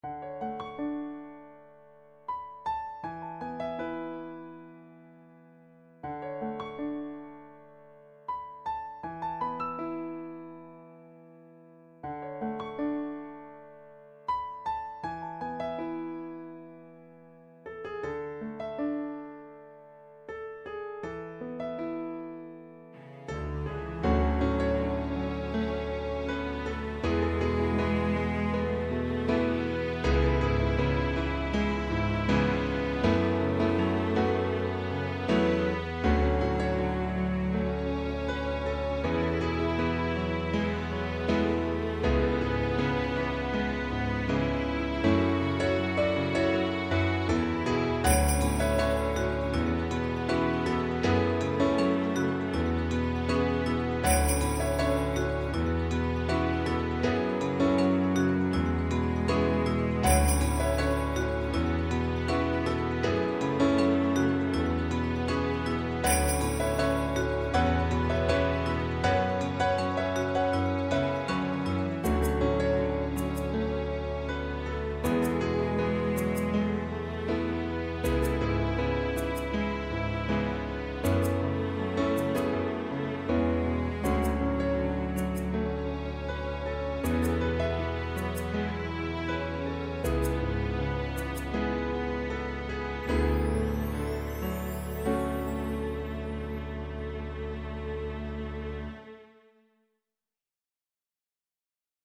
ピアノとストリングス主体のスローテンポの曲です。